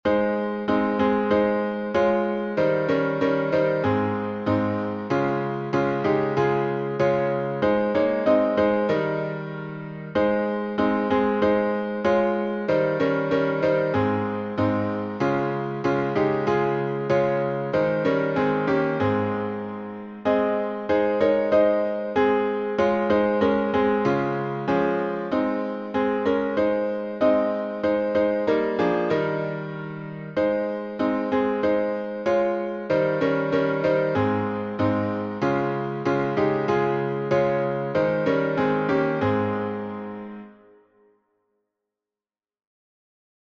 Key signature: A fla